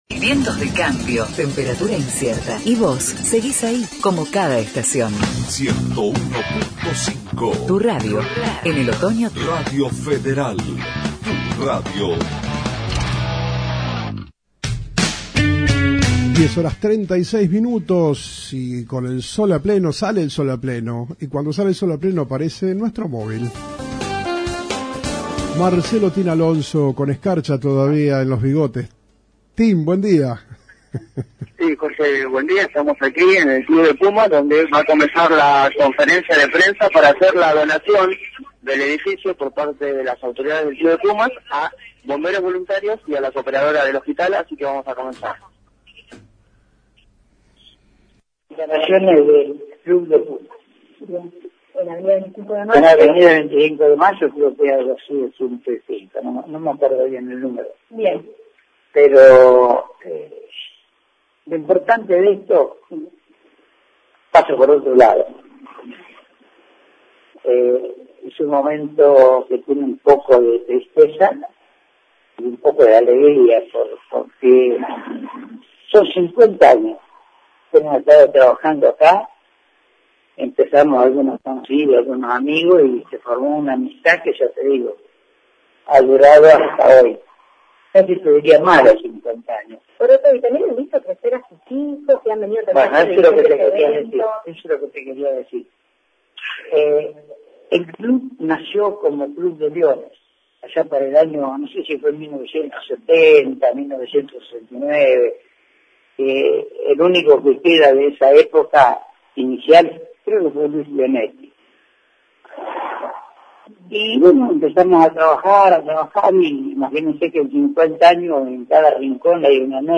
Conferencia e Prensa